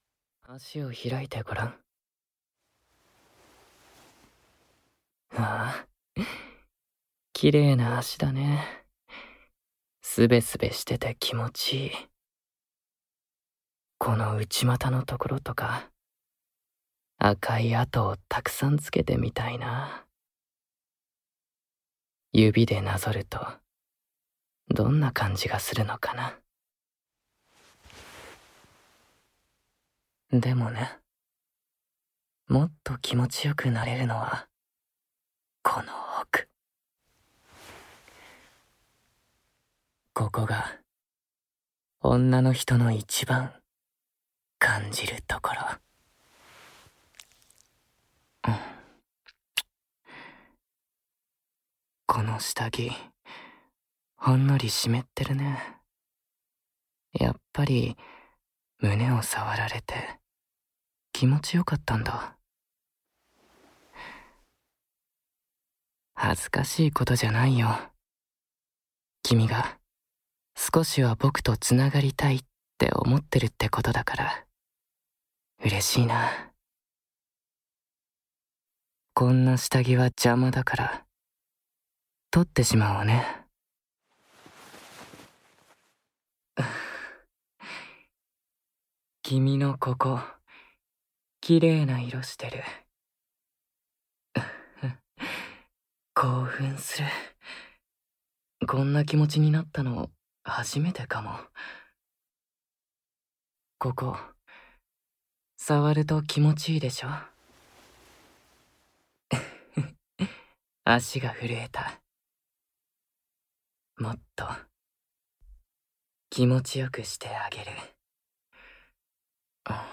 ●18歳以上推奨　●全編ダミーヘッドマイクにて収録